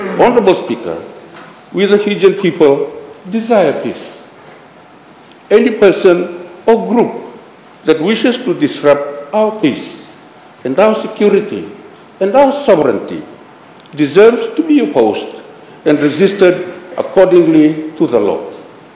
During his address on the budget debate, Tikoduadua claims these individuals continually incite and propagate the Republic of Fiji Military Forces to take power, essentially to remove the elected government.
Minister for Home Affairs, Pio Tikoduadua.